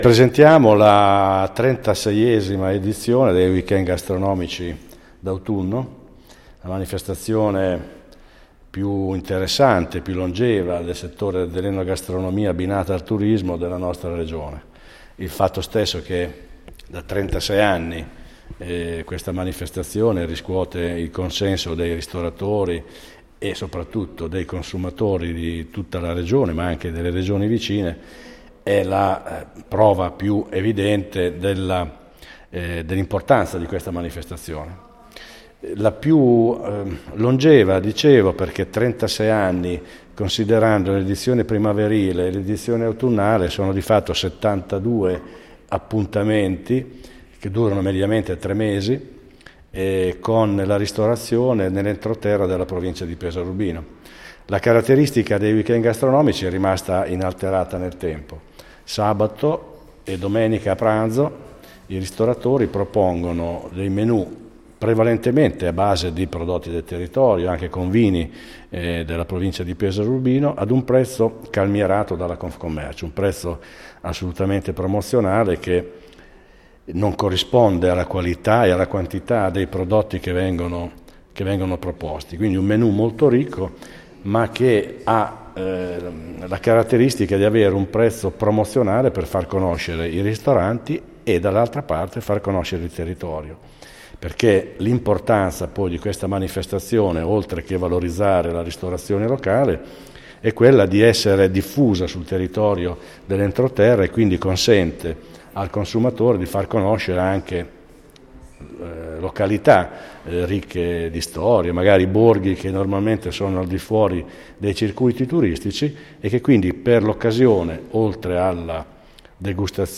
Le interviste a